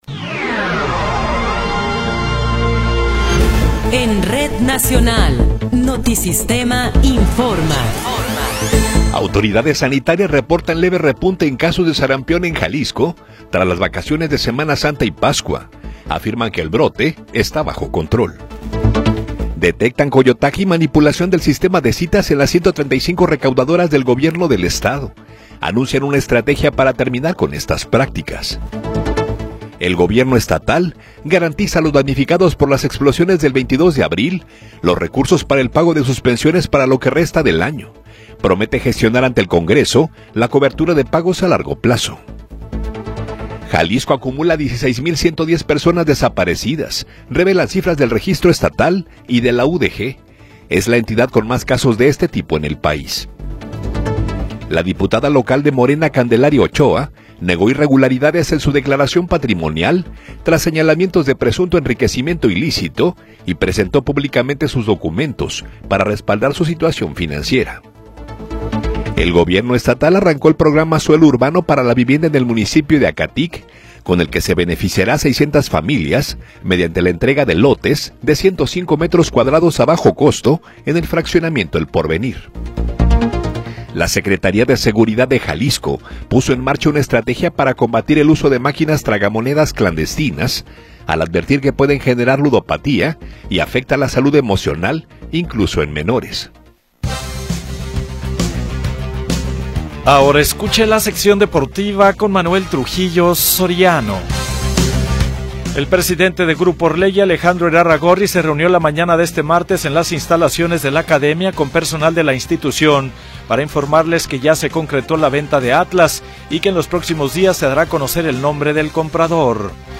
Noticiero 21 hrs. – 21 de Abril de 2026